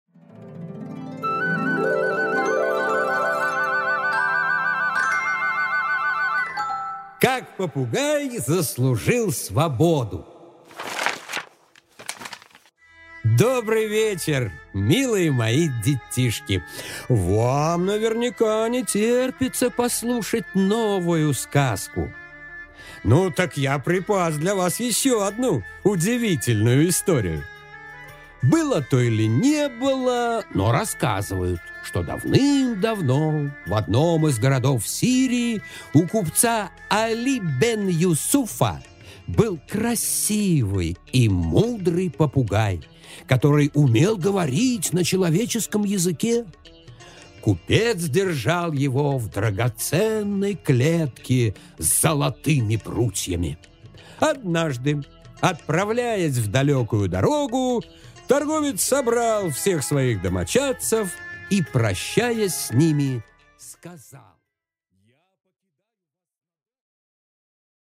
Аудиокнига Как попугай заслужил свободу?